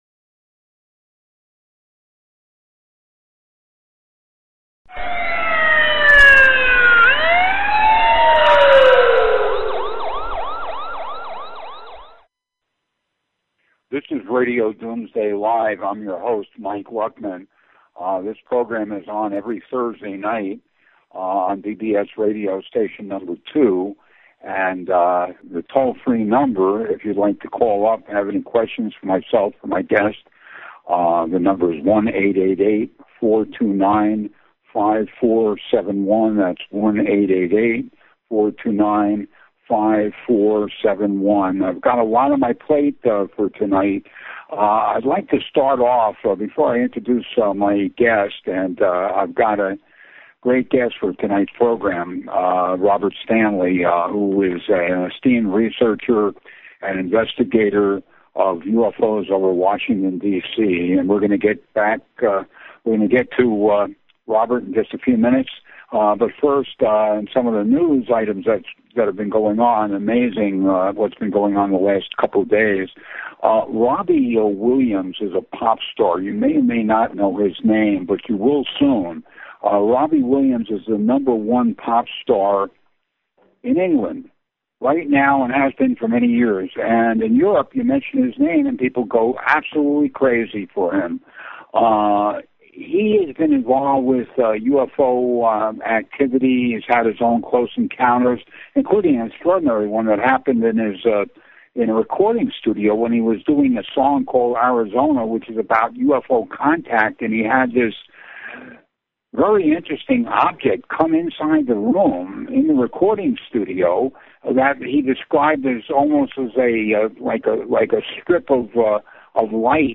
Talk Show Episode, Audio Podcast, Starship_One_Radio and Courtesy of BBS Radio on , show guests , about , categorized as